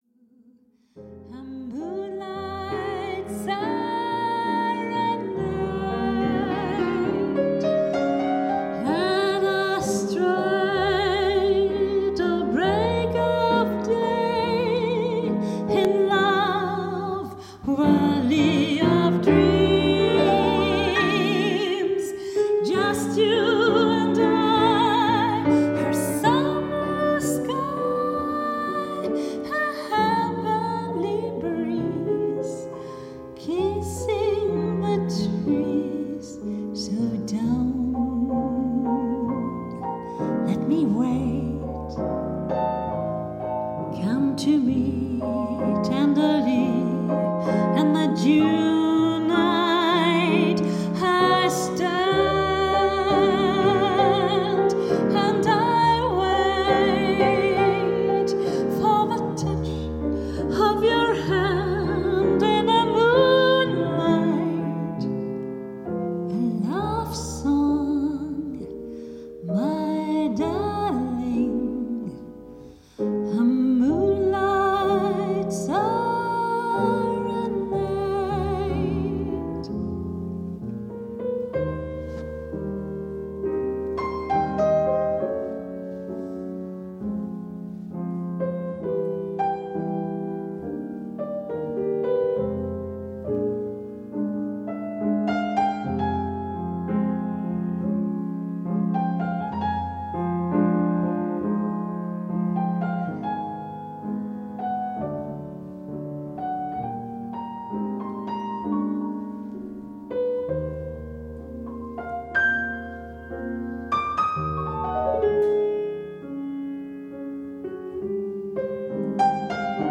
Hochzeitssängerin Hannover/Niedersachsen